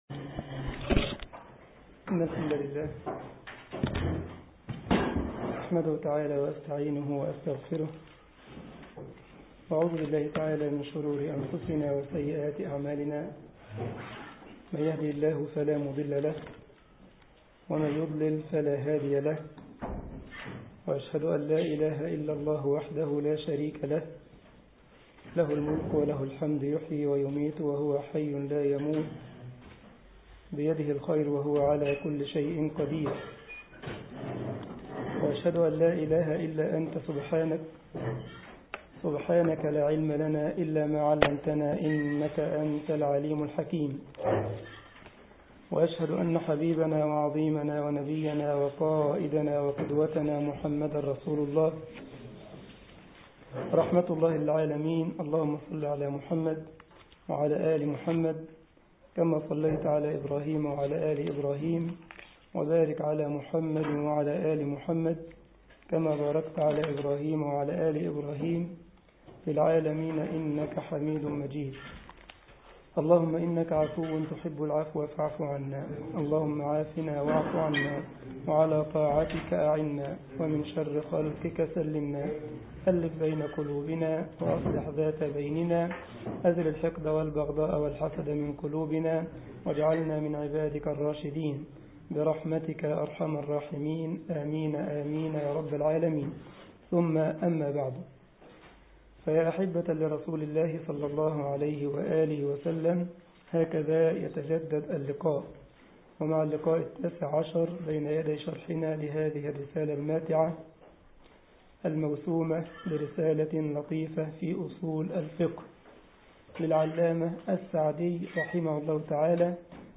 الجمعية الإسلامية بالسارلند ـ ألمانيا درس